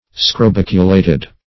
Search Result for " scrobiculated" : The Collaborative International Dictionary of English v.0.48: Scrobiculate \Scro*bic"u*late\, Scrobiculated \Scro*bic"u*la`ted\, a. [L. scrobiculus, dim. of scrobis a ditch or trench.]